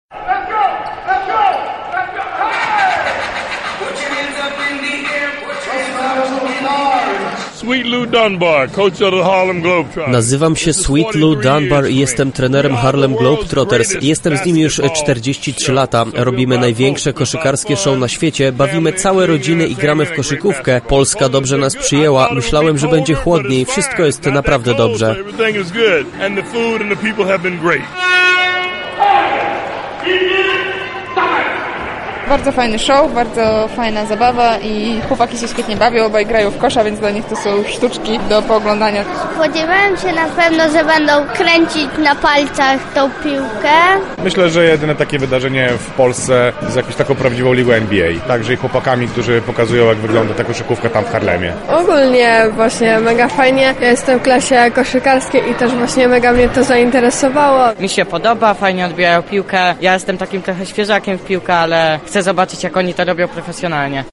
Ich popisy w hali Globus oglądało z trybun kilka tysięcy osób.